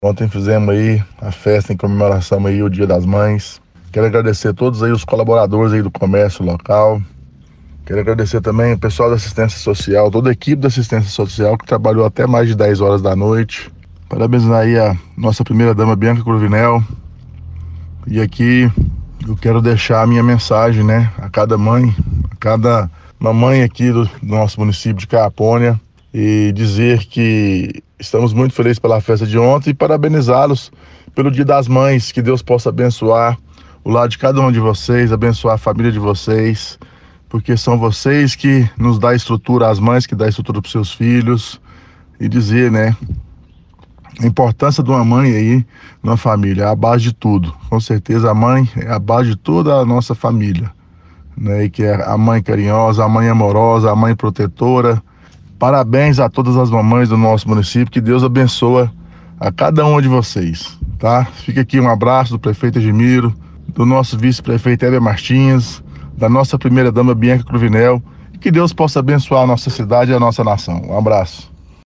Confira fala do Prefeito Argemiro Rodrigues sobre o evento realizado.